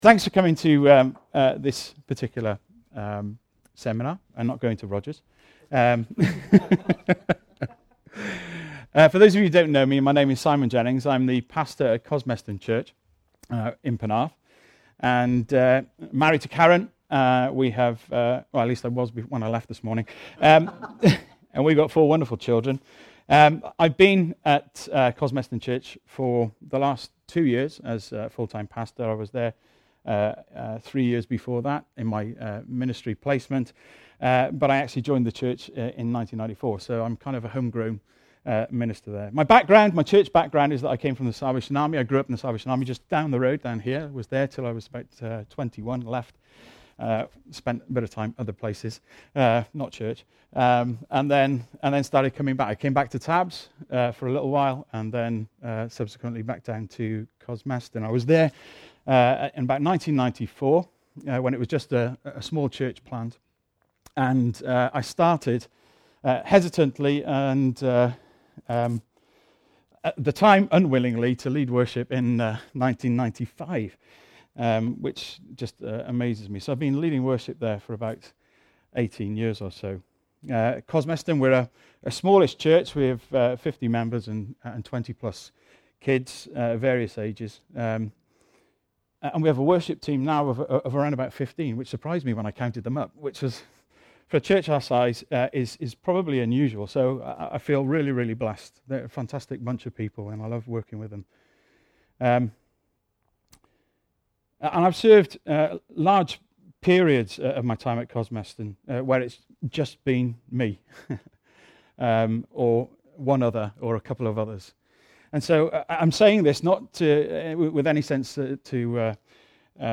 Seminar SJ Main